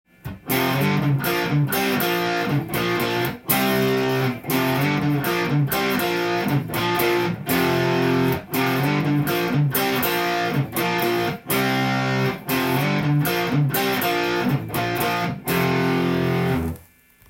譜面通り弾いてみました
モダンロックで７０年代の雰囲気がする渋い曲です。
Gmのkeyです。
４拍目の裏からギターリフが始まります。